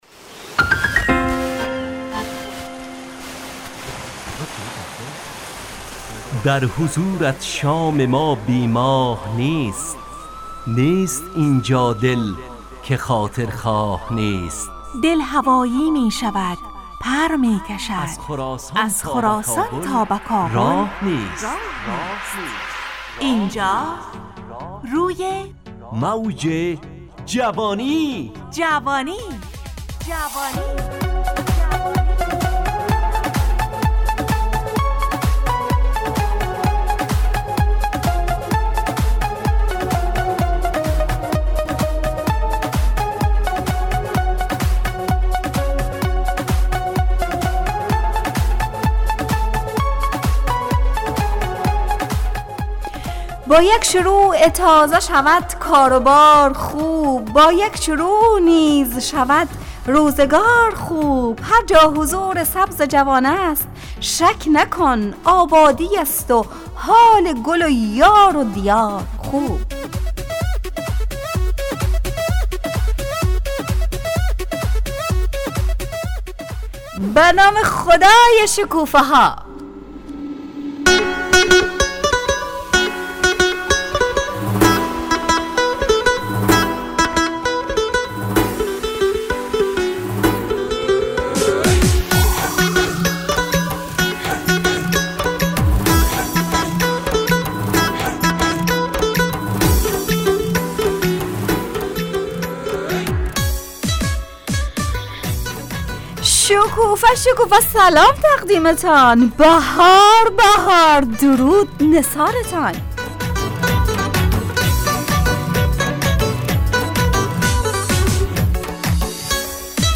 از شنبه تا پنجشنبه ازساعت 17 الی 17:55 طرح موضوعات روز، وآگاهی دهی برای جوانان، و.....بخشهای روزانه جوان پسند. همراه با ترانه و موسیقی .